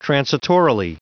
Prononciation du mot transitorily en anglais (fichier audio)
Prononciation du mot : transitorily